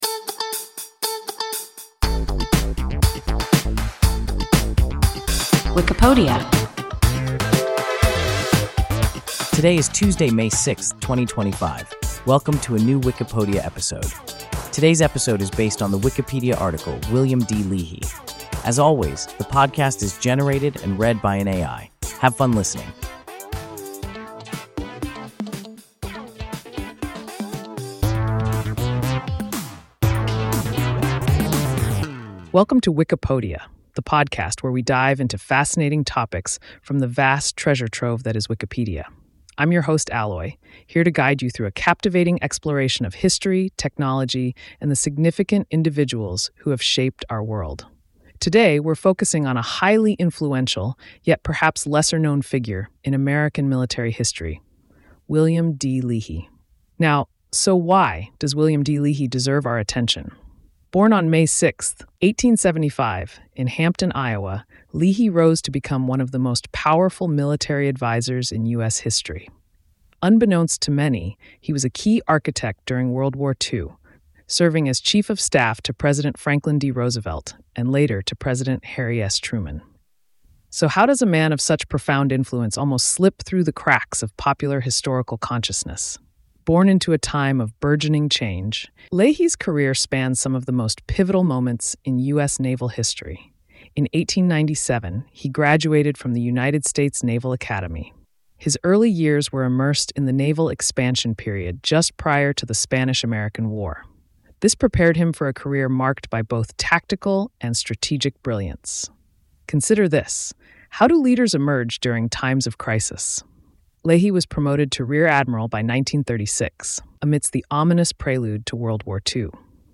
William D. Leahy – WIKIPODIA – ein KI Podcast